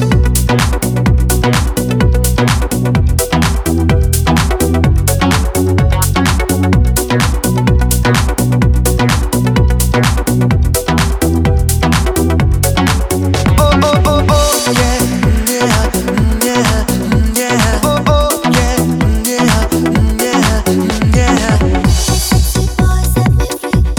For Solo Female No Saxophone Pop